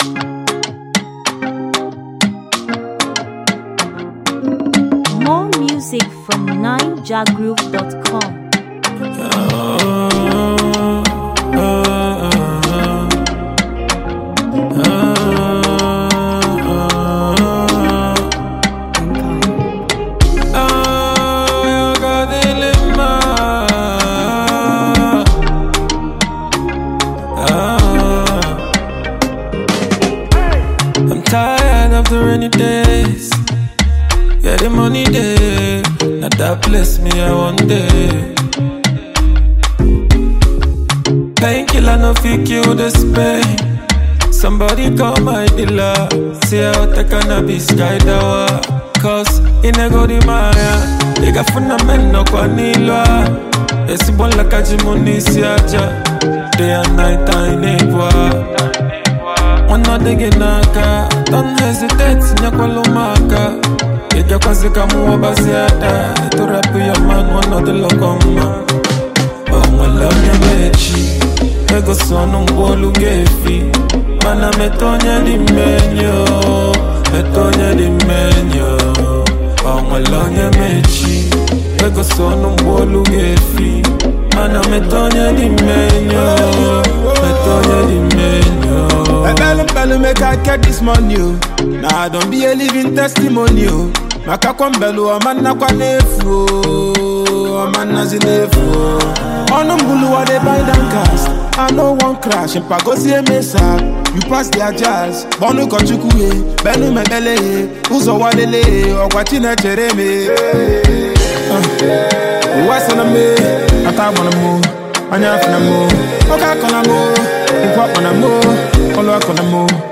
indigenous rap